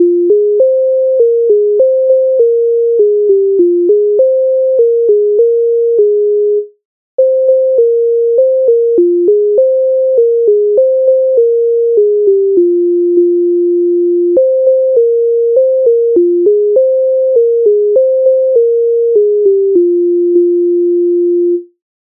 MIDI файл завантажено в тональності f-moll
Ой з-за гори кам'яної Українська народна пісня з обробок Леонтовича с,118
Ukrainska_narodna_pisnia_Oj_z_za_hory_kamianoi.mp3